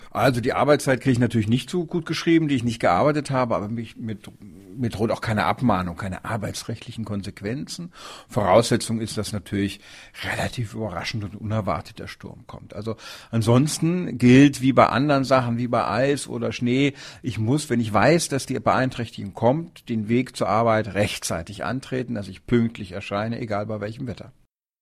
DAV, O-Töne / Radiobeiträge, Ratgeber, , ,